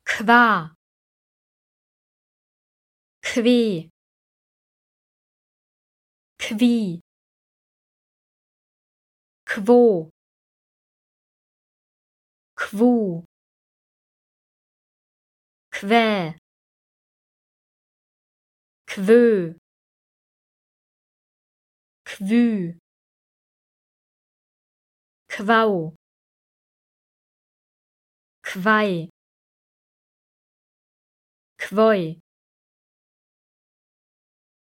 mit Q [kv]
Audio-32-Konsonantenverbindungen-Silben-KV-Modul-2.mp3